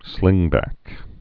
(slĭngbăk)